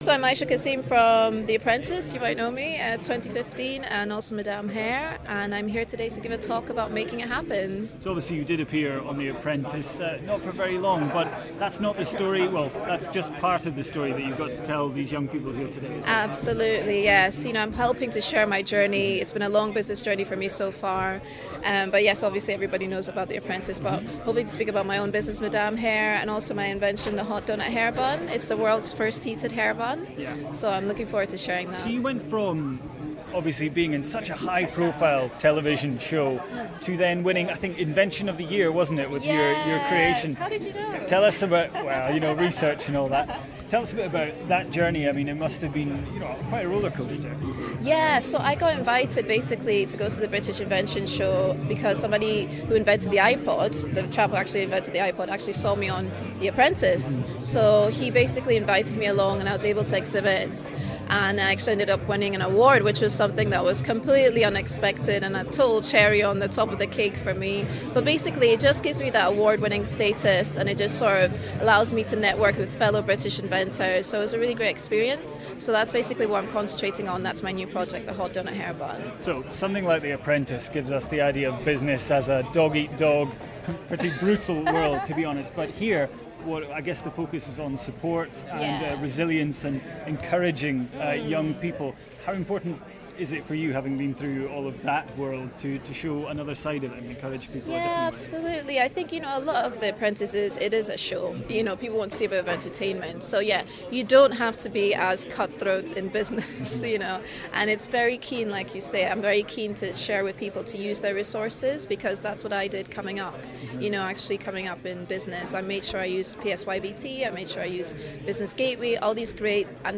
Speaks at Elevator Conference 2015
addressed around 1000 young budding entrepreneurs at the AECC today